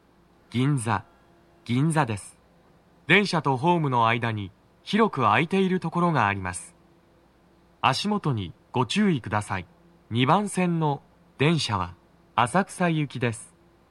スピーカー種類 TOA天井型
🎵到着放送
足元注意喚起放送が付帯されており、多少の粘りが必要です。